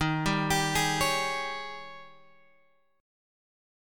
D# 11th